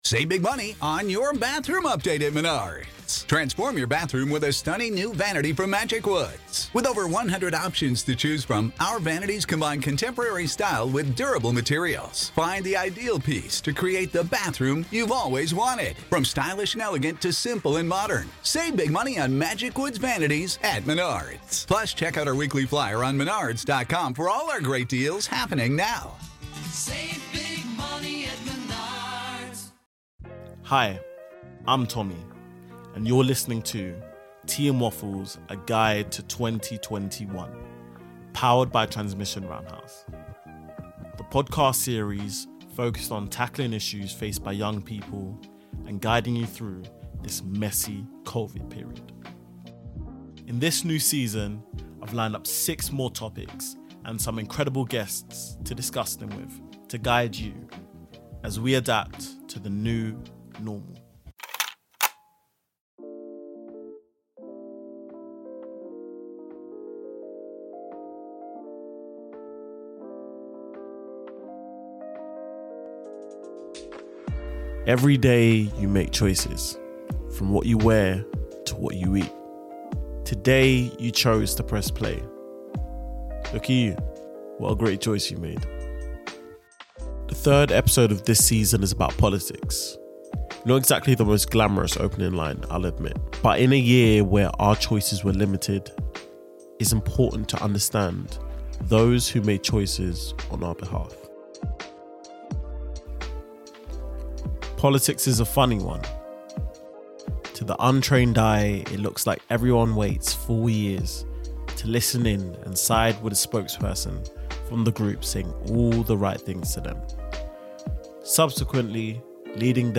Headliner Embed Embed code See more options Share Facebook X Subscribe In this episode I'm joined with Councillors Grace Williams from Waltham Forest and Tim Cobbett from Kingston to discuss the relationship between politics and young people and how young people can impact change in their local area.